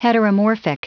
Prononciation du mot heteromorphic en anglais (fichier audio)